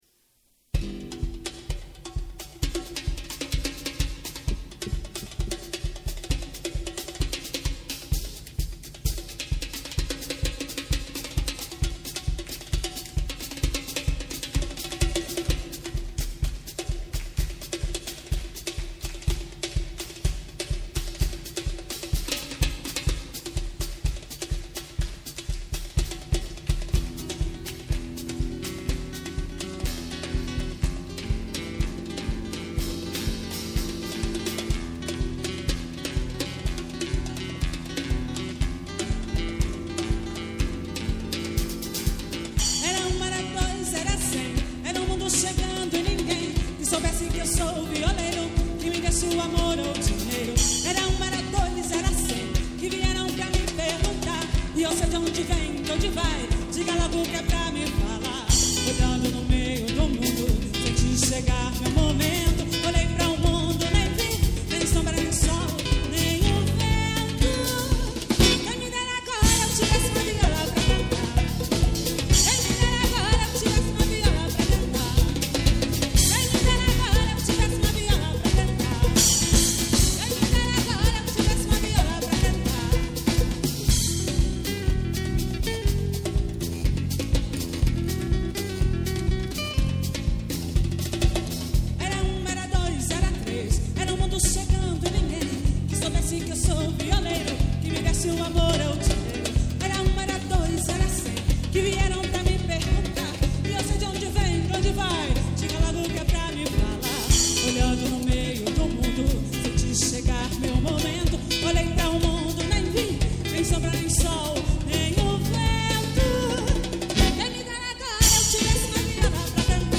MPB - musica popular brasileira